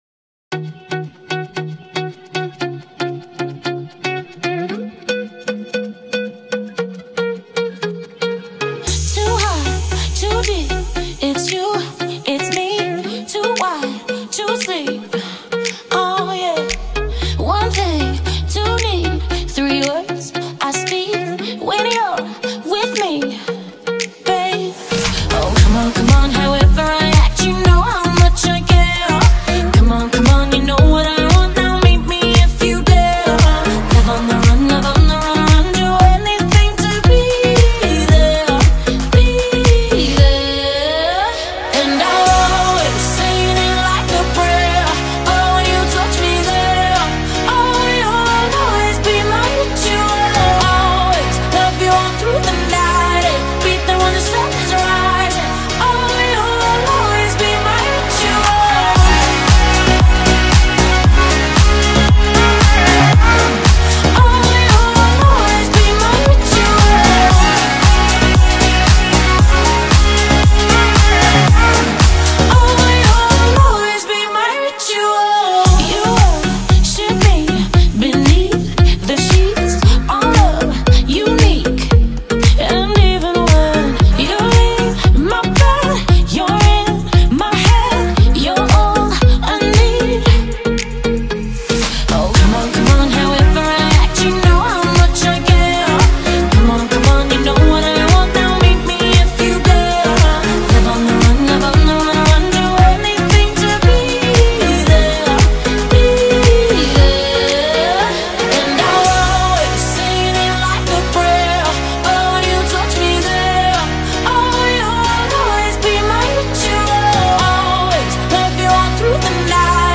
دانلود آهنگ پاپ خارجی